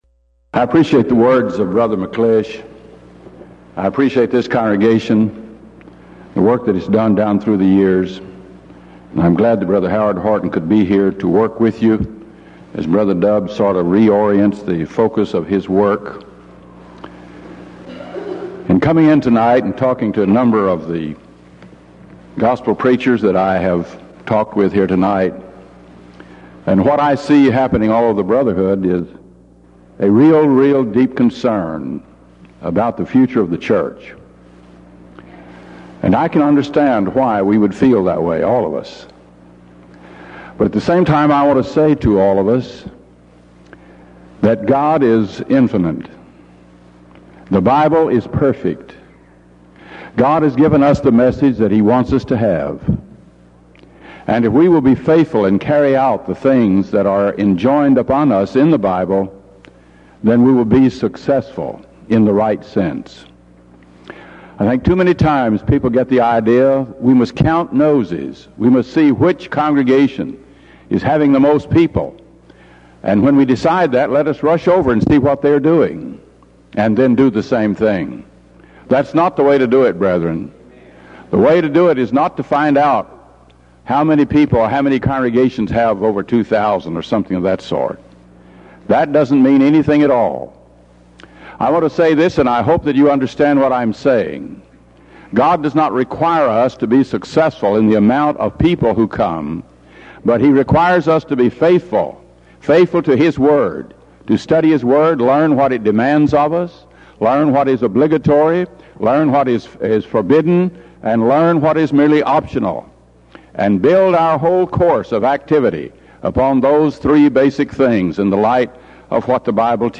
Series: Denton Lectures Event: 1992 Denton Lectures